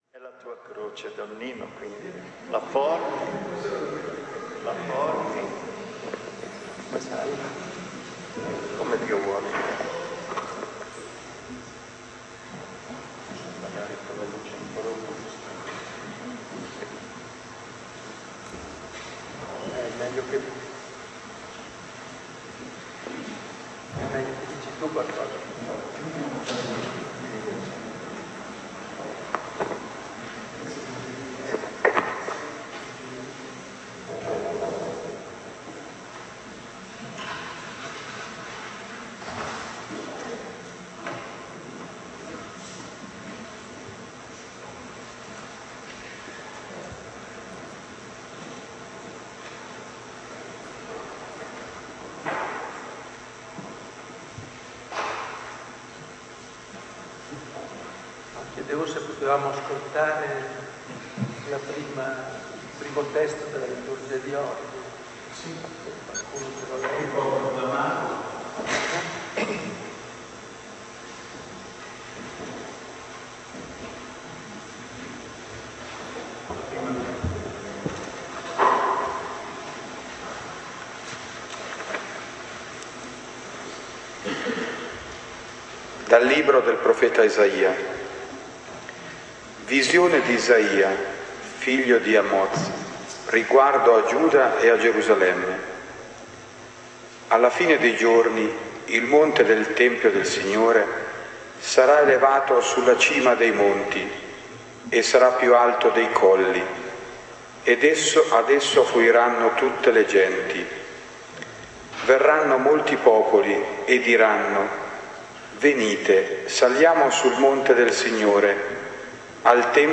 Ritiro Spirituale Domenica 2 dicembre 2007.